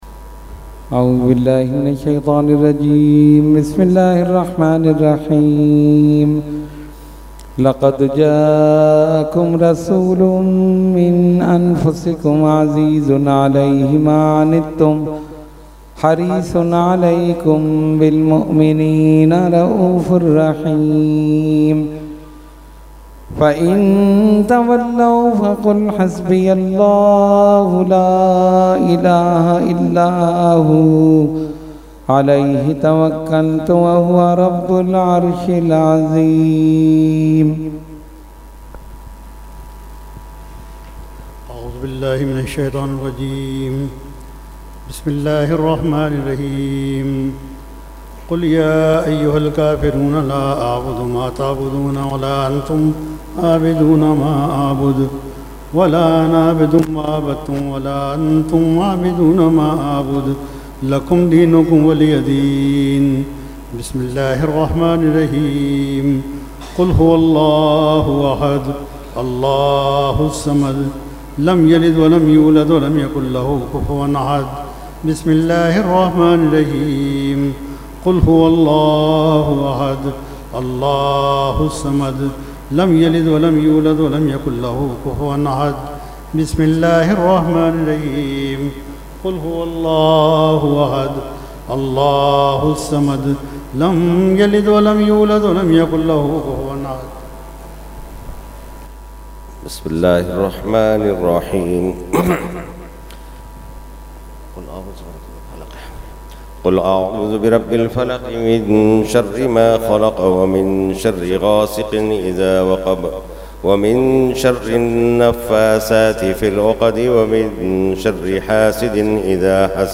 Fatiha And Dua – Urs Ashraful Mashaikh 2020 – Dargah Alia Ashrafia Karachi Pakistan
13-Fatiha And Dua.mp3